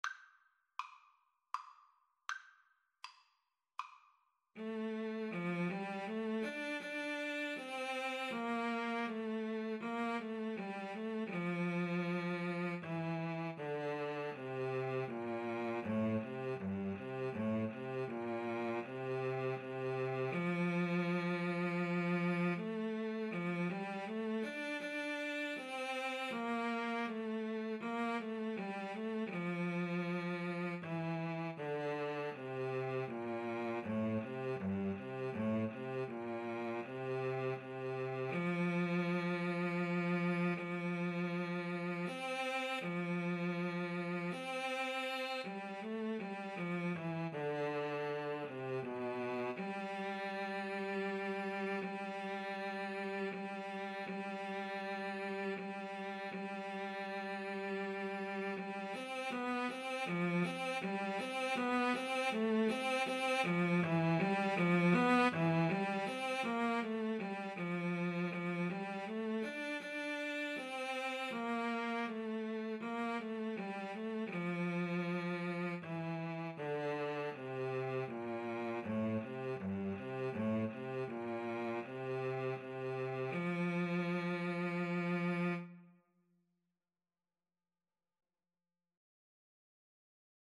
Andante
F major (Sounding Pitch) (View more F major Music for Flute-Cello Duet )
3/4 (View more 3/4 Music)
Classical (View more Classical Flute-Cello Duet Music)